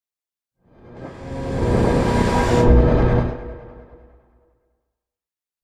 conjuration-magic-sign-circle-intro.ogg